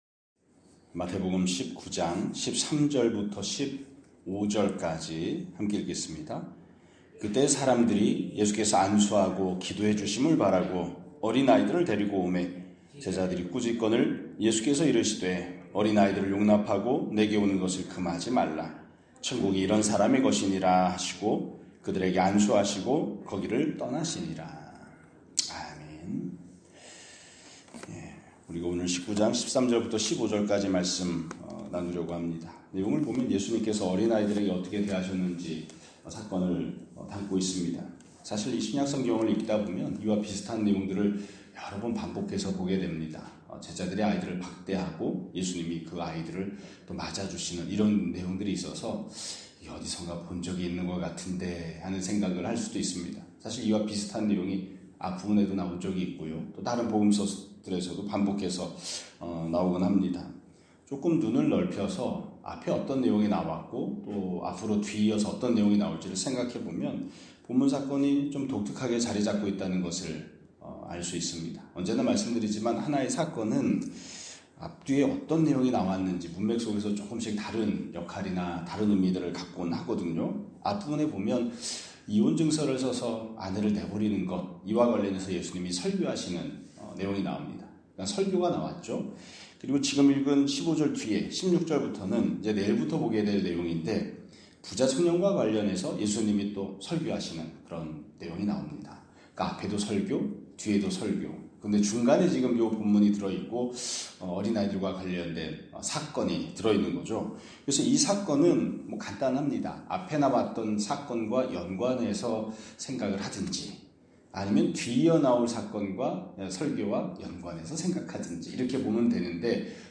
2026년 1월 6일 (화요일) <아침예배> 설교입니다.